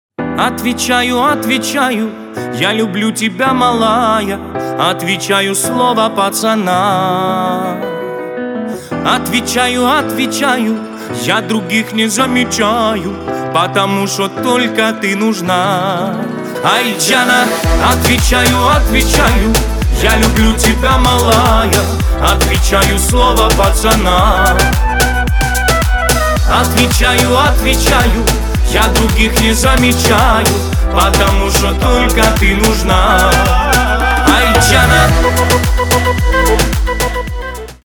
• Качество: 320, Stereo
поп
веселые
заводные
восточные